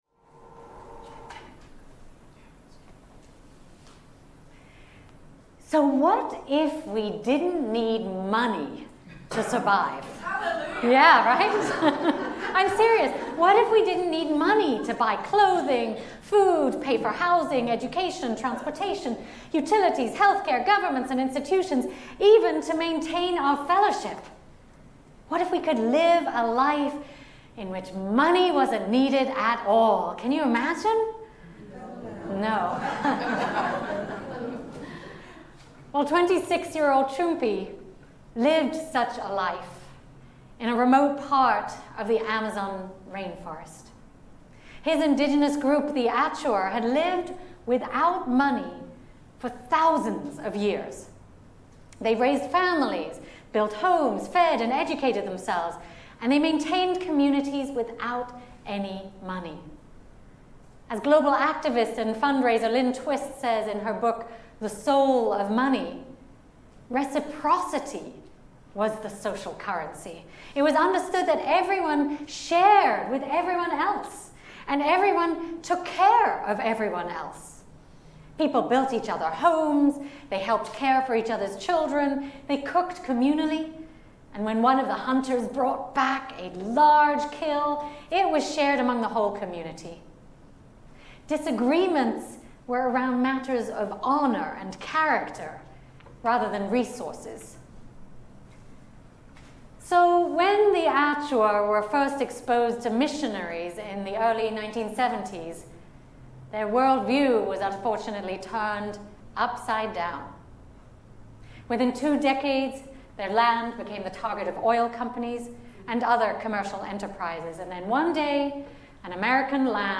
Sermon.m4a